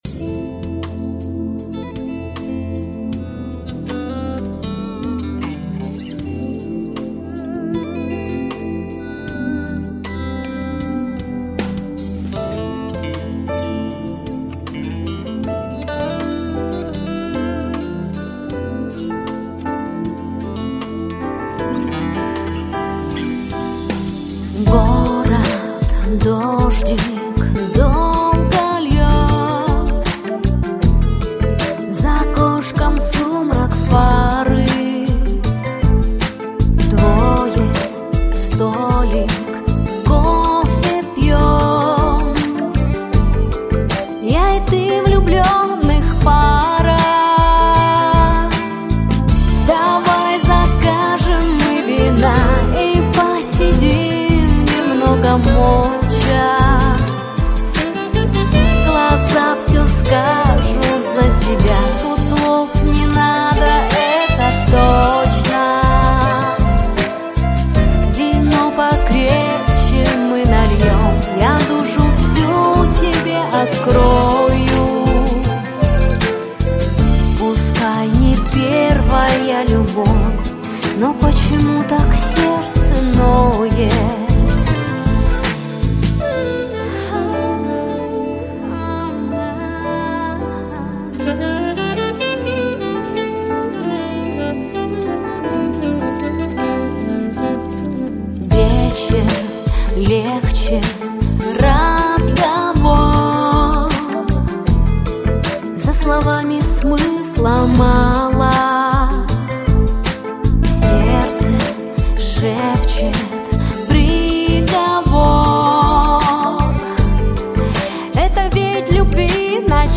• Жанр: Блюз